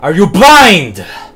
combobreak_M8qyU14.mp3